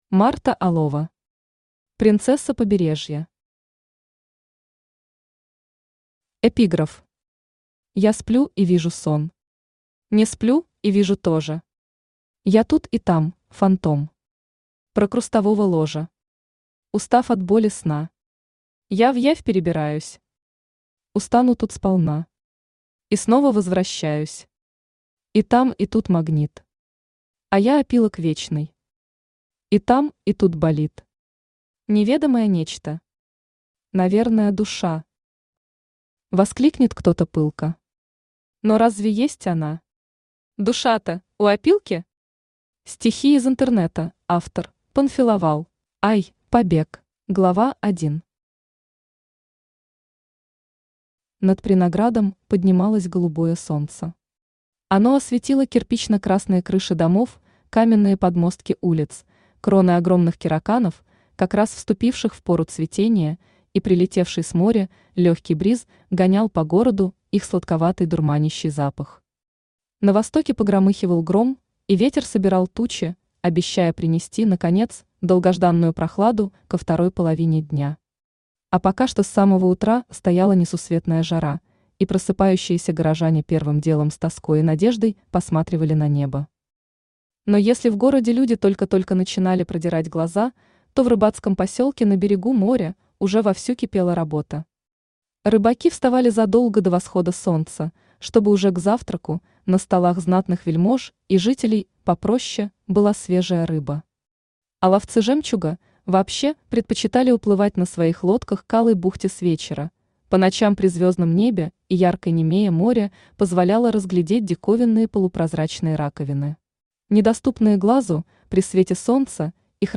Аудиокнига Принцесса Побережья | Библиотека аудиокниг
Aудиокнига Принцесса Побережья Автор Марта Юрьевна Алова Читает аудиокнигу Авточтец ЛитРес.